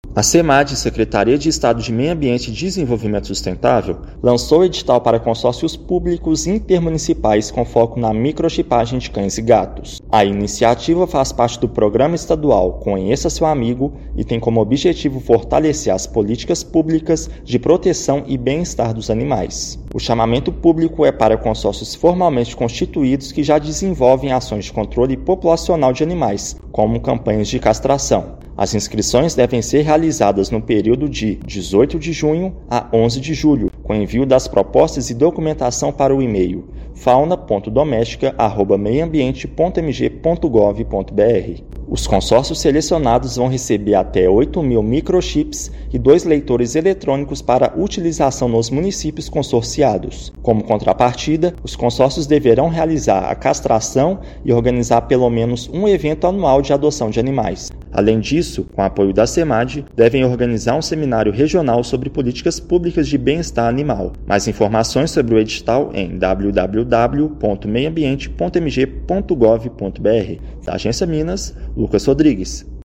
Iniciativa da Semad visa ampliar ações de proteção e bem-estar animal com distribuição de microchips e leitores eletrônicos para consórcios intermunicipais. Ouça matéria de rádio.